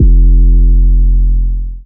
DDW6 808 5.wav